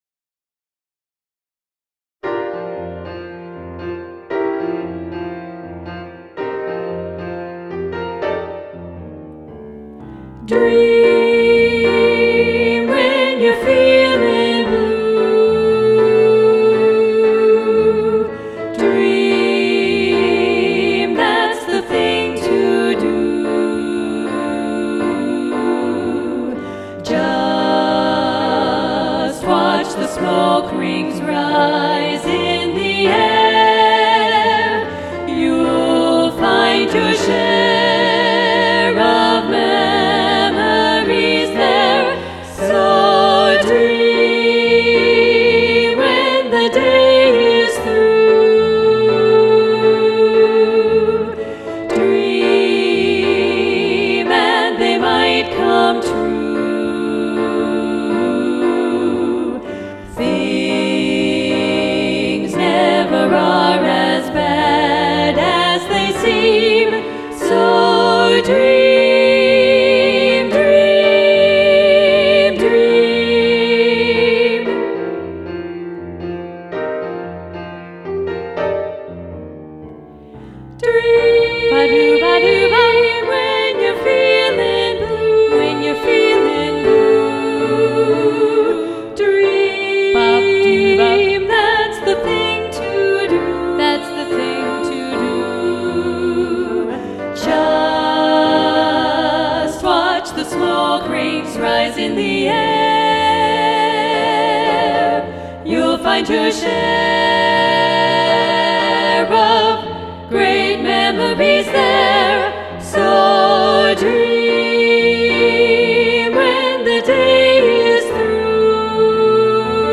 Dream SSAA – Alto 1 Muted – arr. Jay Althouse